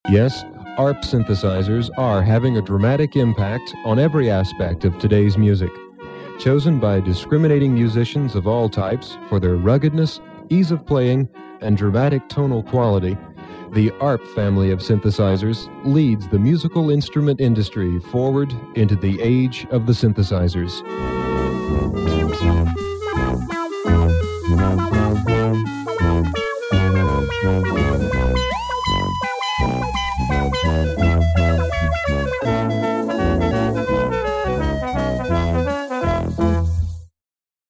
ARP 2600 Demo Tape - Conclusion